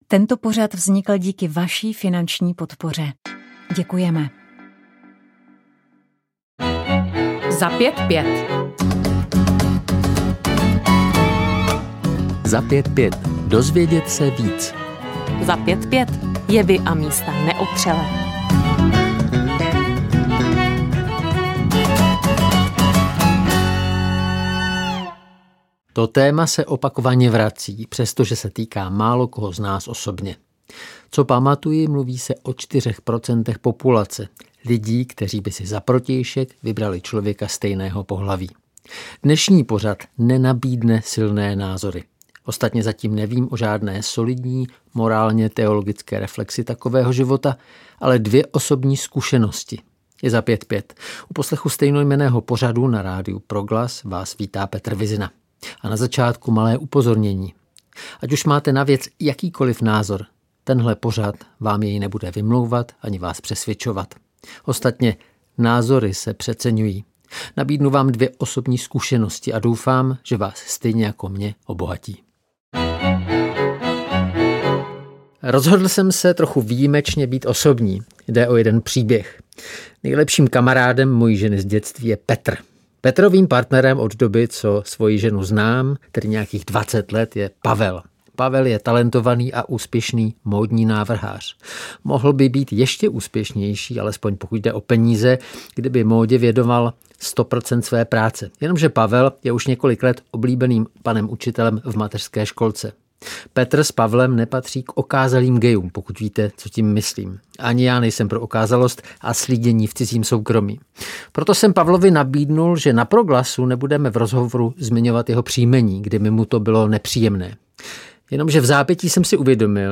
Pořad v souvislostech